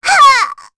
Rehartna-Vox_Damage_08.wav